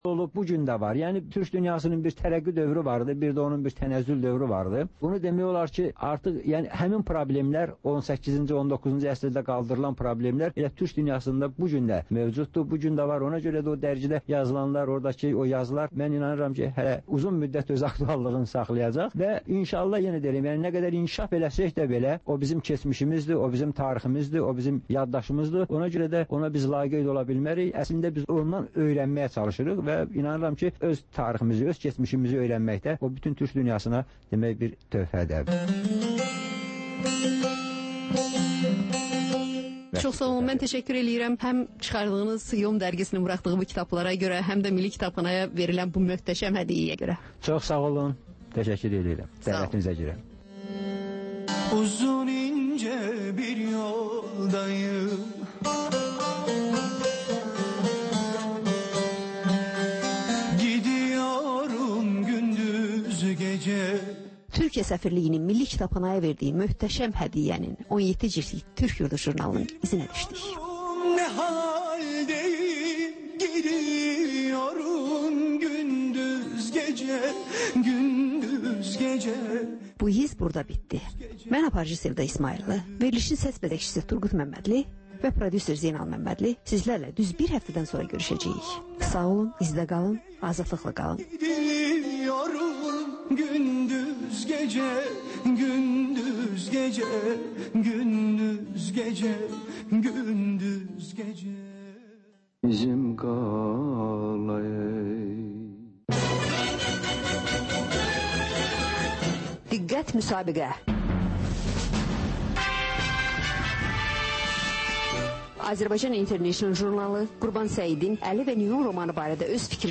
Ölkənin tanınmış simalarıyla söhbət.